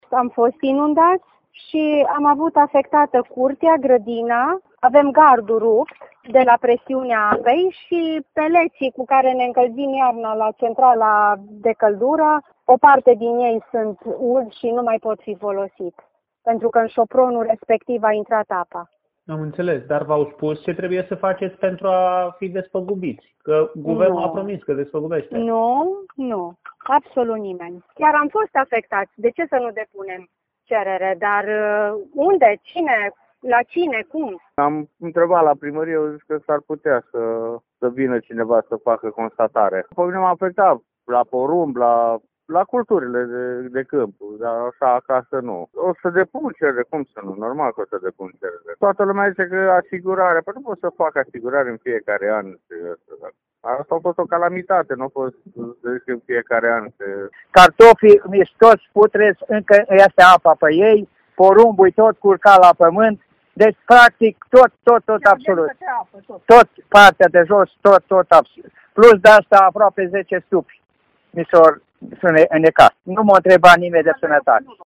Prefectul de Timiș, Liliana Oneț a explicat care este procedura, arătând că responsabilitatea evaluării pagubelor le revine comitetelor locale pentru situații de urgență: